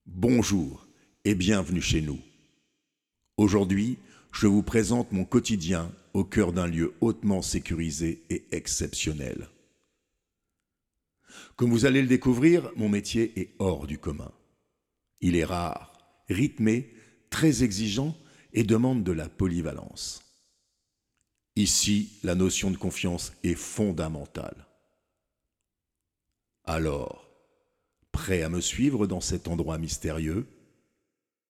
Ma voix parlée normale